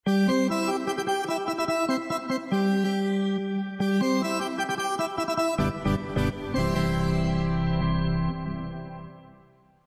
piano bcb 4 Meme Sound Effect
piano bcb 4.mp3